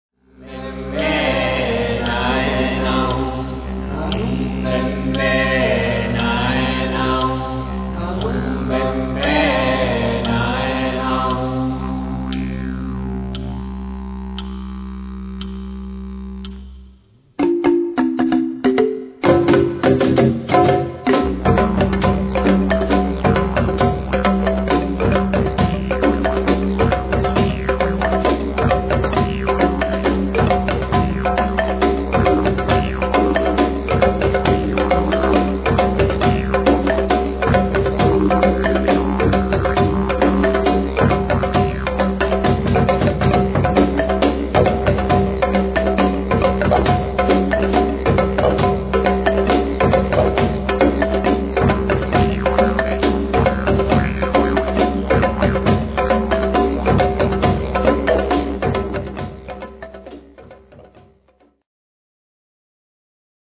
Didgeridu, Tin Whistle, Clave Cubana, Moxeño
Djembe, Marímbula, Derbake, Balafón, Semillas, Chaschas
Guitarra eléctrica.